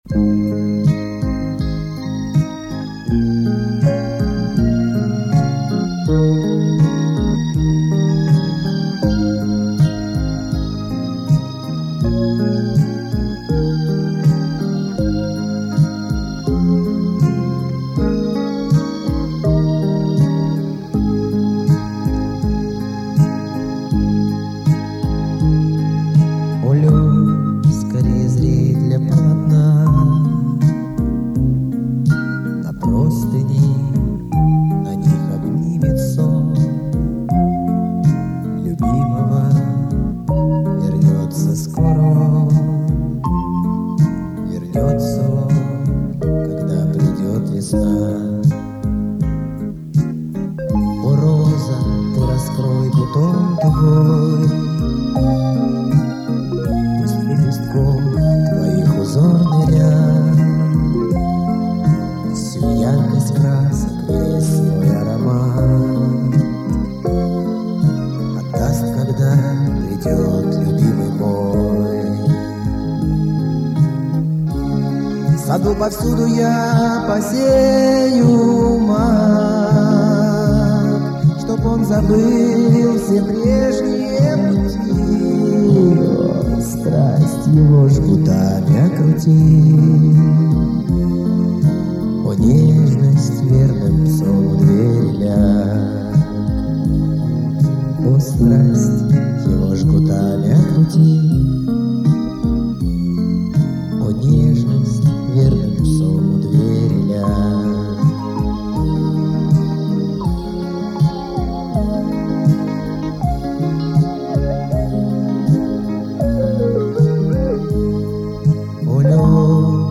Тут звучание вроде помягше